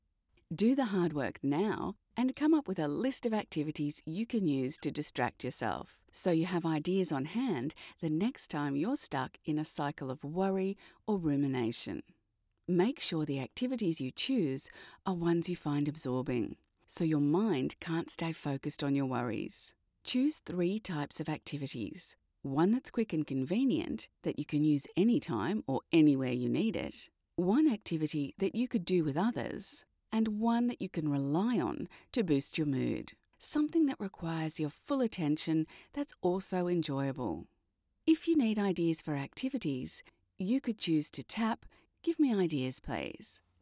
Her voice is always Authentic, Trustworthy, and Warm. Home Studio.
Standard Australian Accent, British Accent, Standard American Accent, Characters